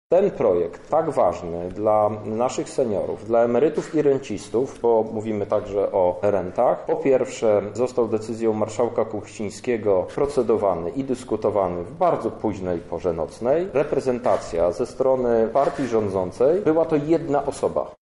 -Partia rządzącą okazała pogardę nie tyle wobec projektu, co w stosunku do emerytów i rencistówtwierdzi Krzysztof Hetman, prezes Zarządu Wojewódzkiego PSL w Lublinie,